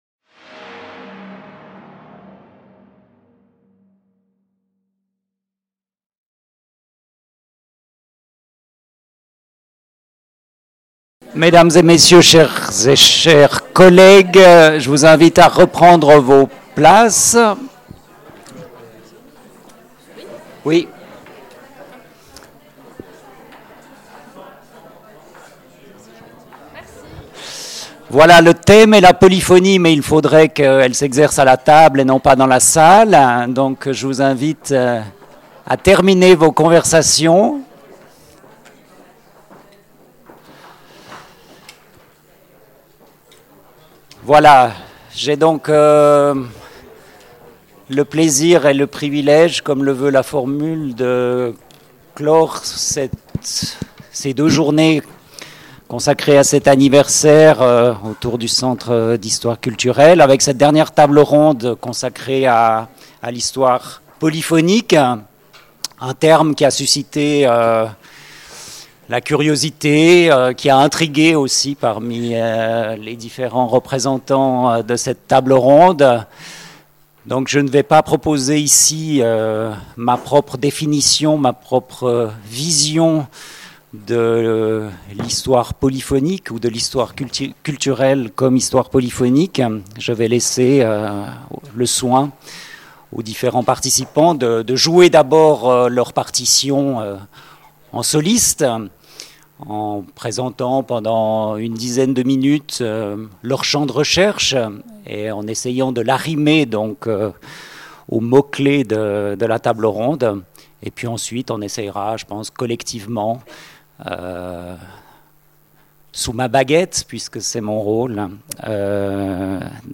14 et 15 novembre 2012 Table ronde - Une histoire polyphonique ?